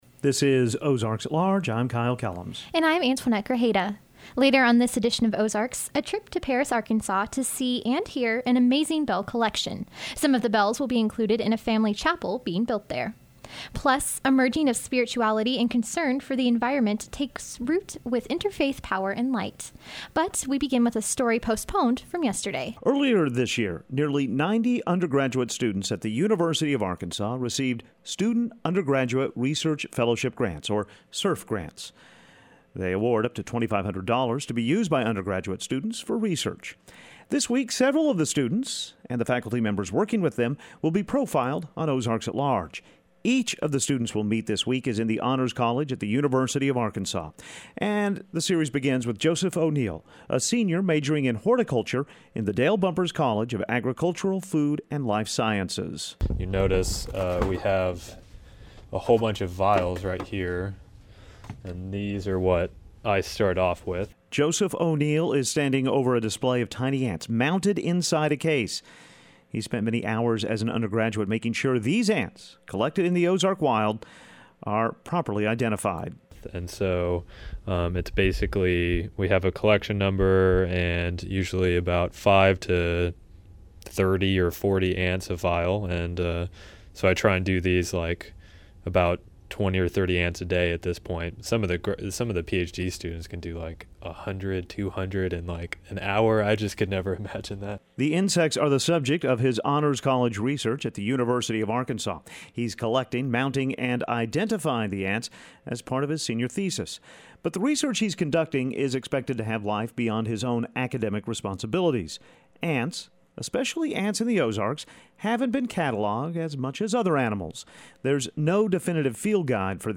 On this edition of Ozarks, the first in a series of interviews with University of Arkansas Honors College students who received a Student Undergraduate Research Fellowship (SURF) grant of up to $2,500